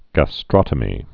(gă-strŏtə-mē)